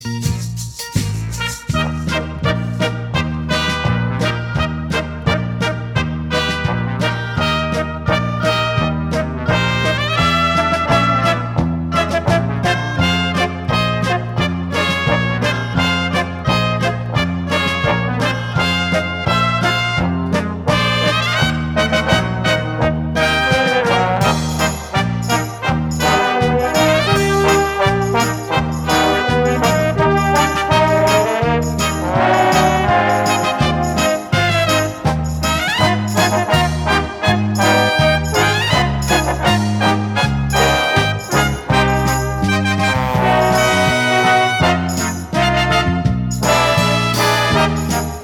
• Качество: 320, Stereo
инструментальные